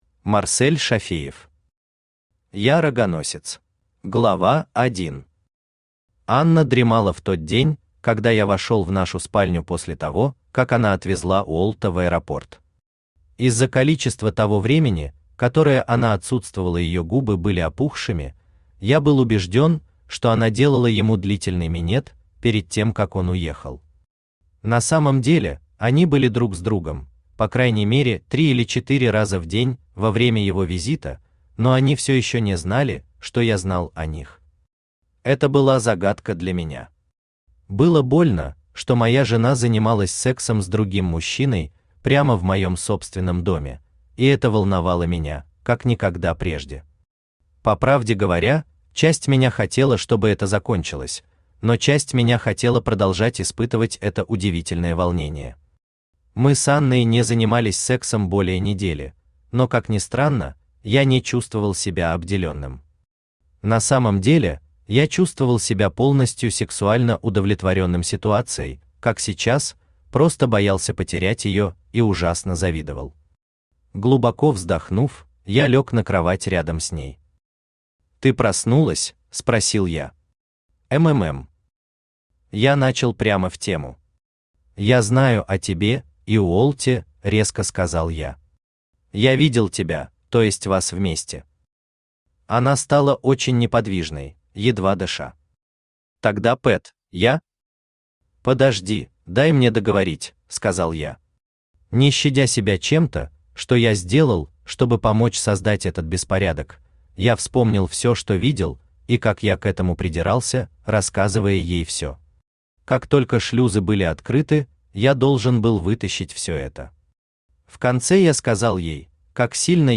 Аудиокнига Я рогоносец | Библиотека аудиокниг
Aудиокнига Я рогоносец Автор Марсель Зуфарович Шафеев Читает аудиокнигу Авточтец ЛитРес.